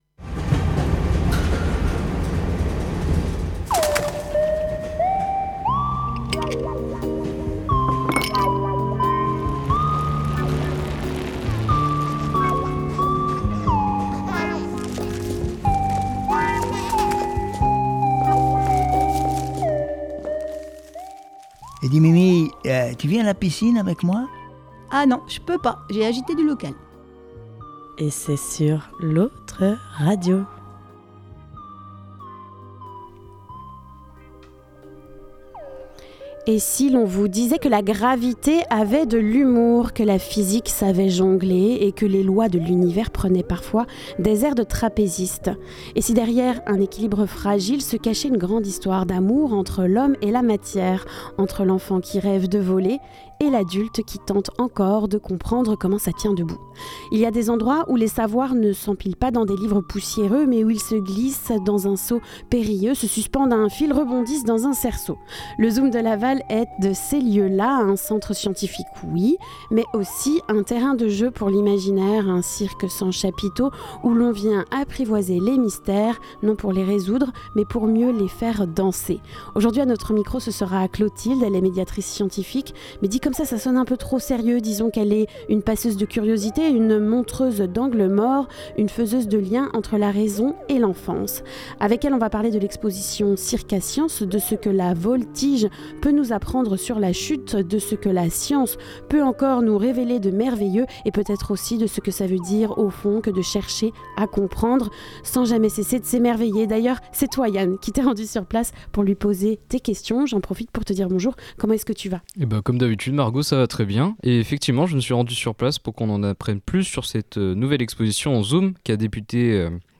Le micro trottoir de la semaine Le reportage Penser Local : Recycler l’eau des piscines : des campings des Pays de la Loire s’engagent Comment limiter les impacts environnementaux liés au tourisme ?